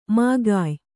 ♪ magāy